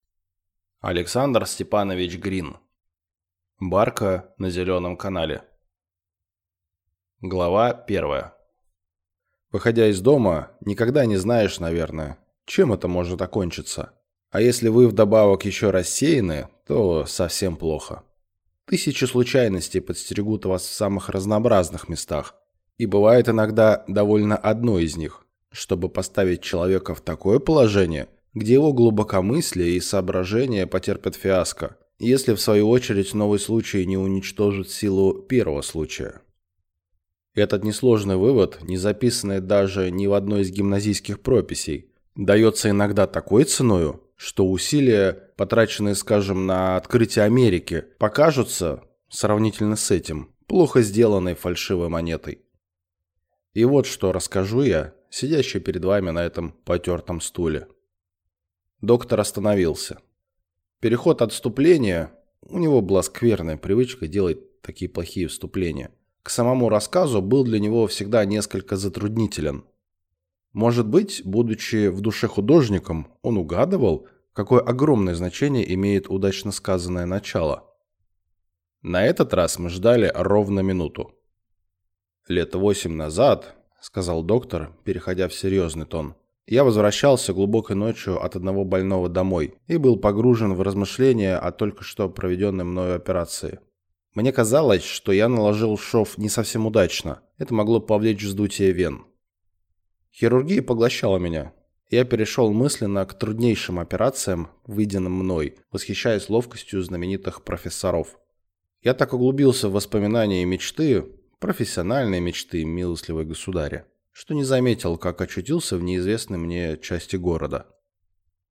Аудиокнига Барка на Зеленом канале | Библиотека аудиокниг